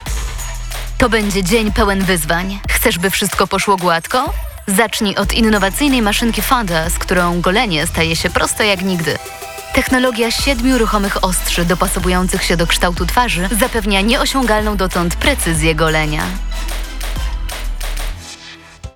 Udzielam głosu do nagrań lektorskich – audiobooki, teksty medytacyjne, reklamy, filmy instruktażowe, dokumentalne i fabularne.